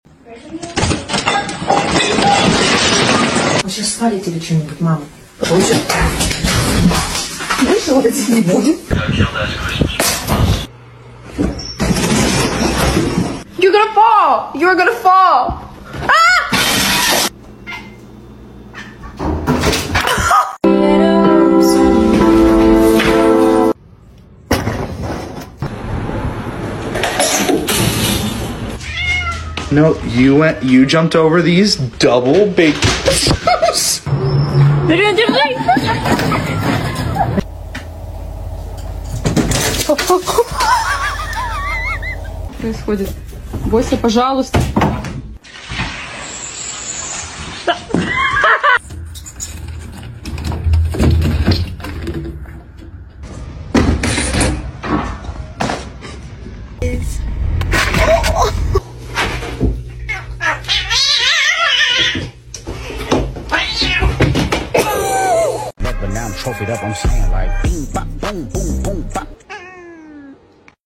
Cat Fall Crazy Funny stuff sound effects free download